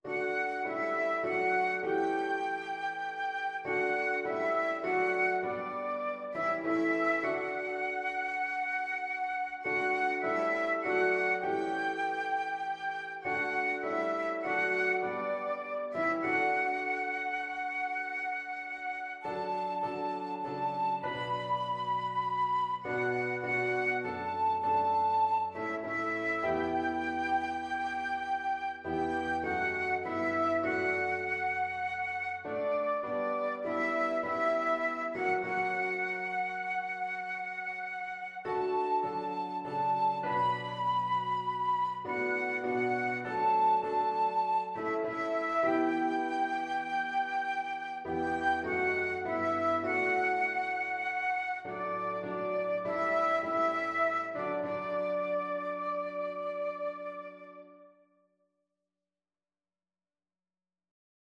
Flute version
4/4 (View more 4/4 Music)
Traditional (View more Traditional Flute Music)